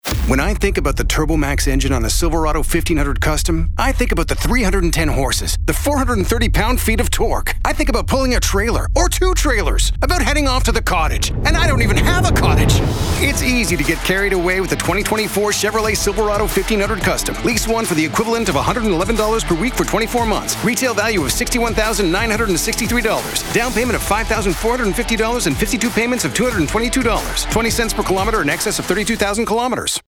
Publicité (Silverado) - ANG